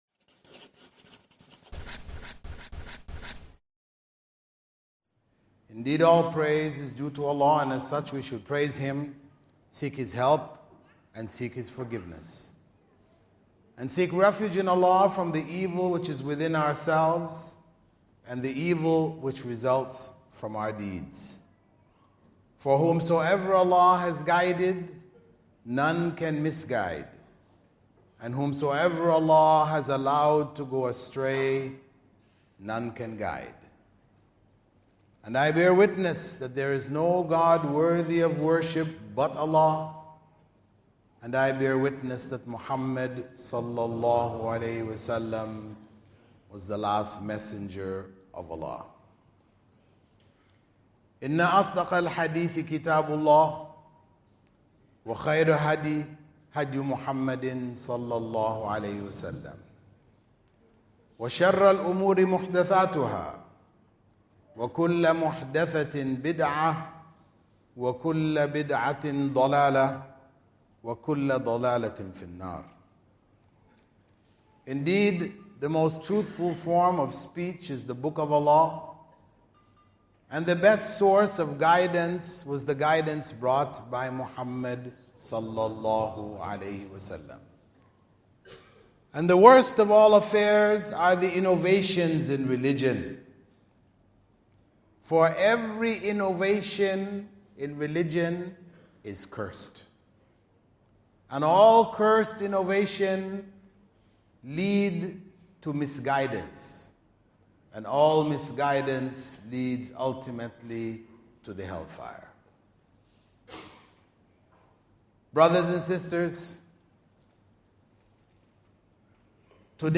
552 views High Quality: Download (408.75 MB) Medium Quality: Download (70.97 MB) MP3 Audio (00:40:58): Download (19.92 MB) Transcript: Download (0.09 MB) Living Islam Between the 2 Extremes Dr. Bilal Philips gave an amazing Friday sermon at Fanar Masjid talking about how to live Islam.